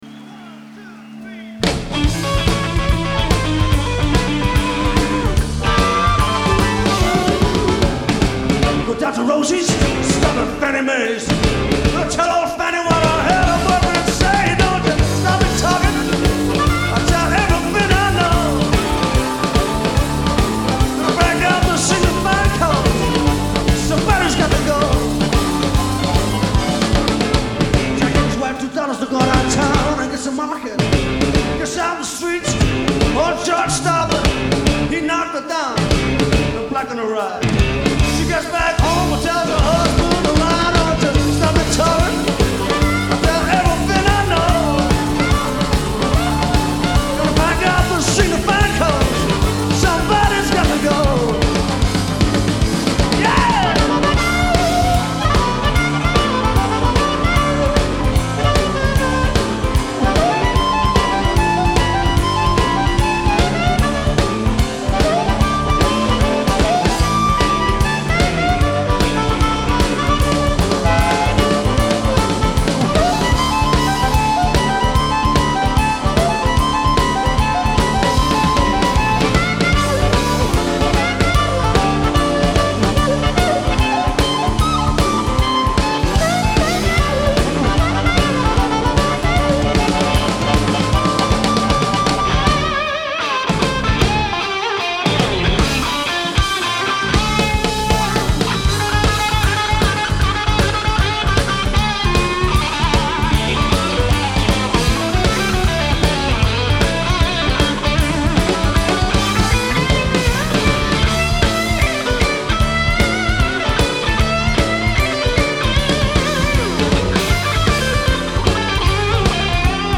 Genre : Blues
Live At The Town & Country Club, London, UK